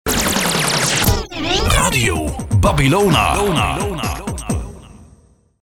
Onze Jingles / Unser Jingles